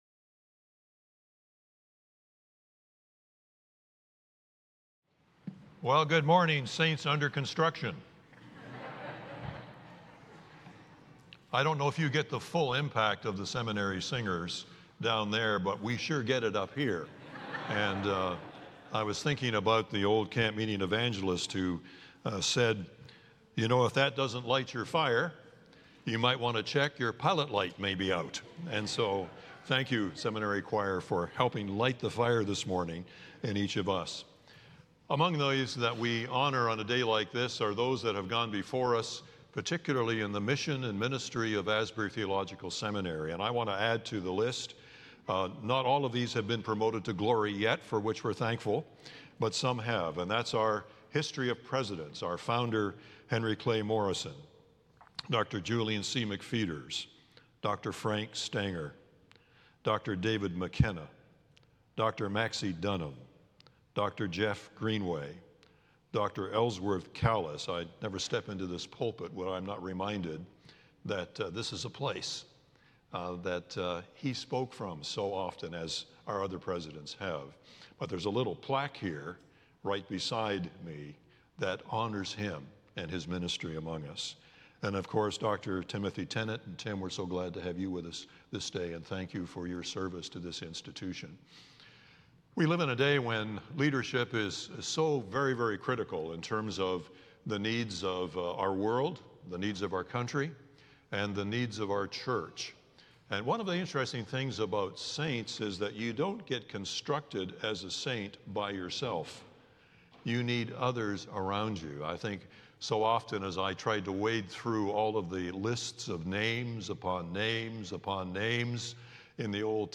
The following service took place on Thursday, October 31, 2024.
Sermon